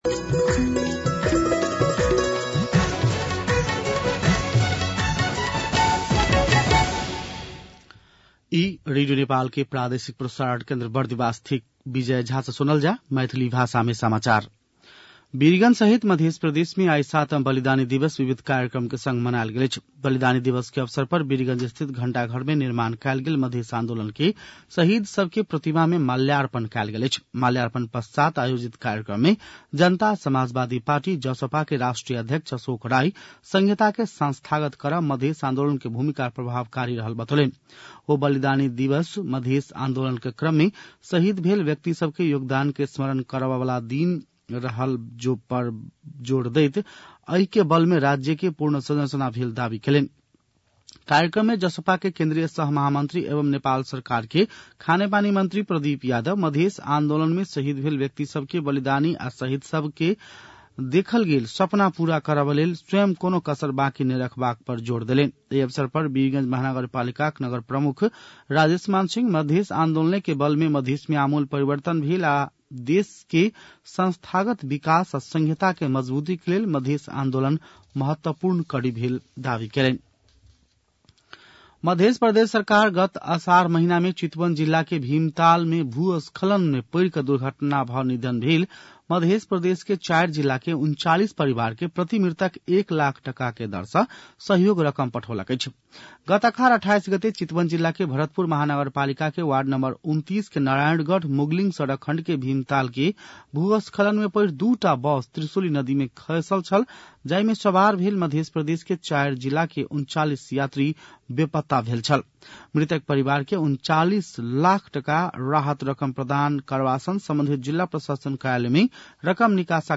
मैथिली भाषामा समाचार : ६ माघ , २०८१
Maithali-News-10-5.mp3